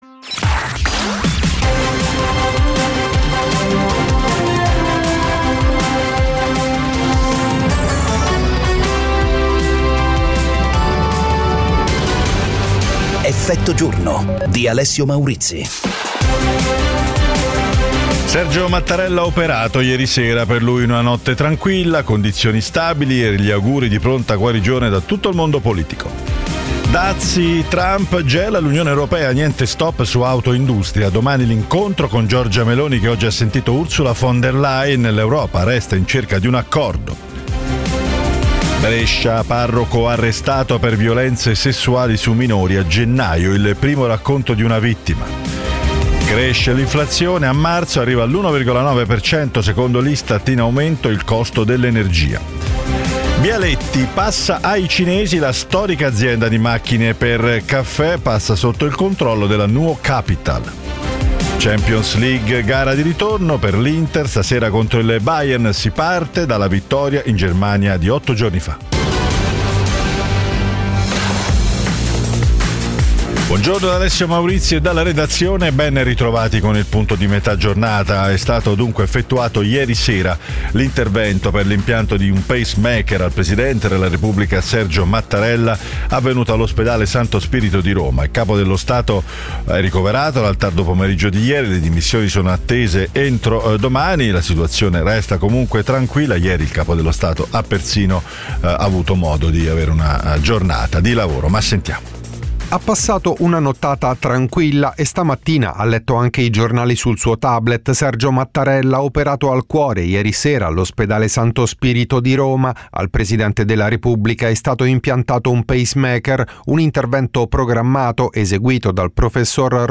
Effetto giorno è la trasmissione quotidiana che getta lo sguardo oltre le notizie, con analisi e commenti per capire ed approfondire l'attualità attraverso ospiti in diretta e interviste: politica, economia, attualità internazionale e cronaca italiana.